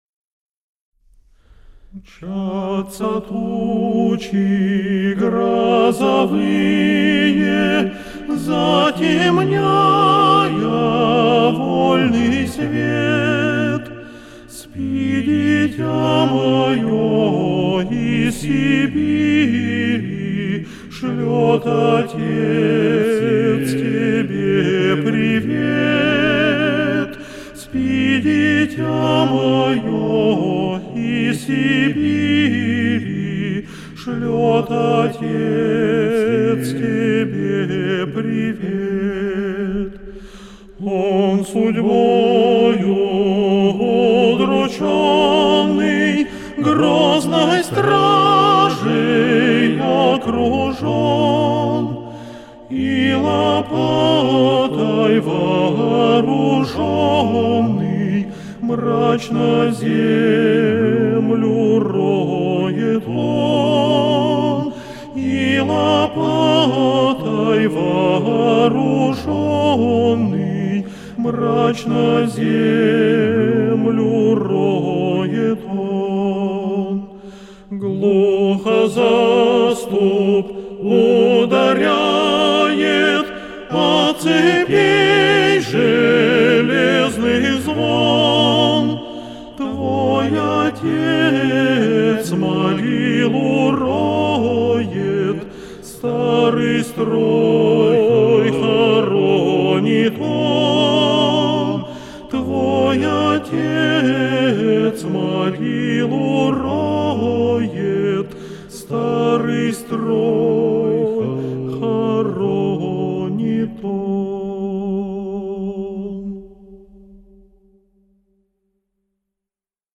שירי ערש   שירים מתורגמים   ארצות שונות   שירי זמרדעים